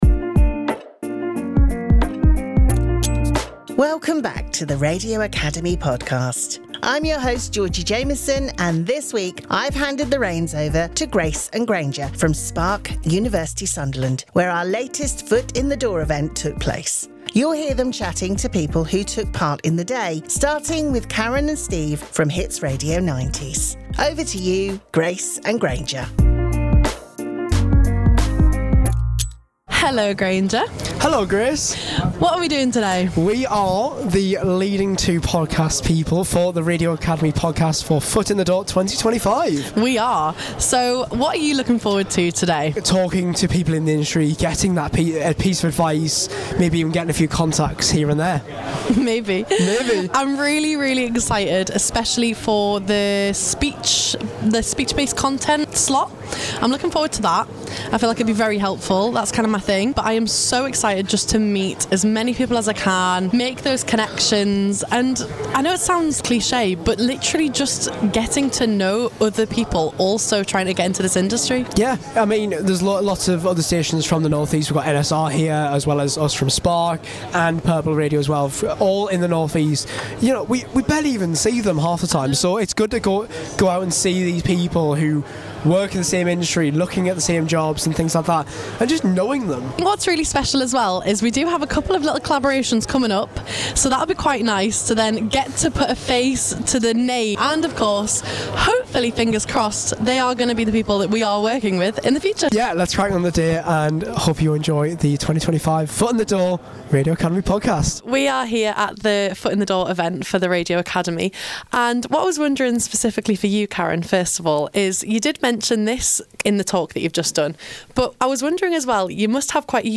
Headliner Embed Embed code See more options Share Facebook X Subscribe Our latest Foot in the Door event was held at The University of Sunderland and this week on the podcast Spark University Sunderland are our guest hosts.